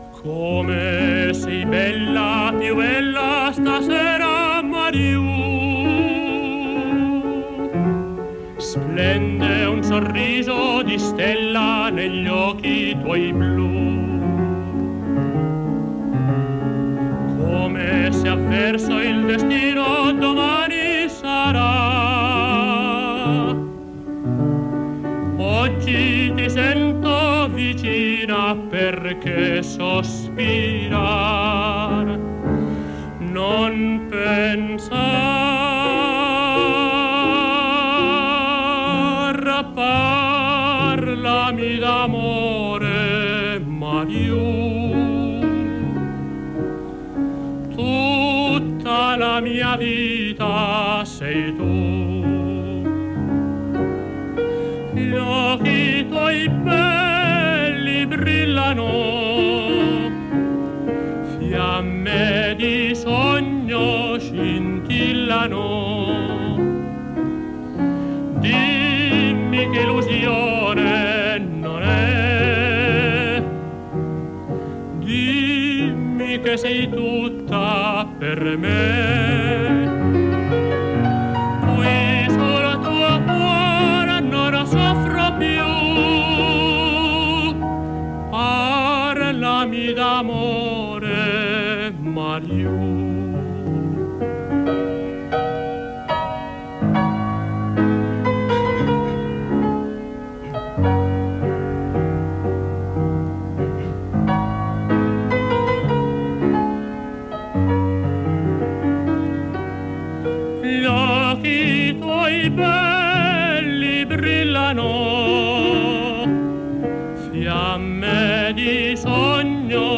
Comiat del programa. Gènere radiofònic Musical